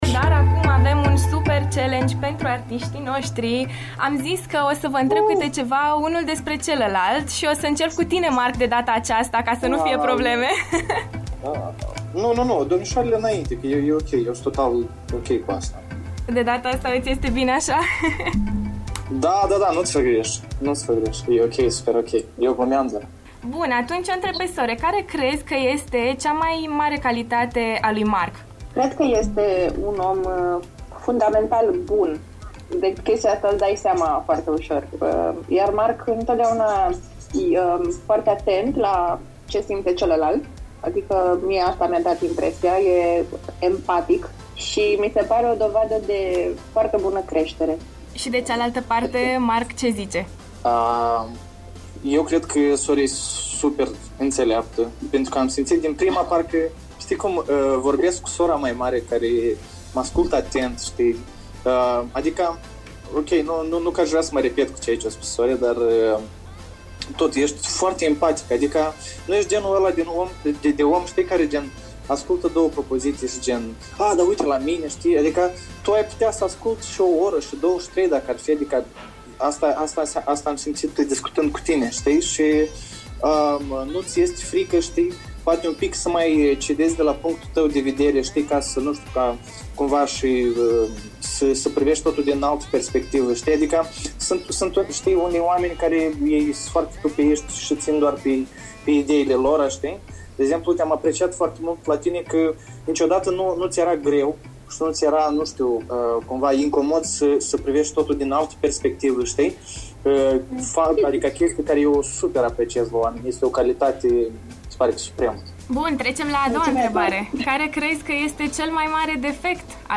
Home Emisiuni After Morning Cât de bine se cunosc Sore și Mark Stam? Challenge LIVE în After Morning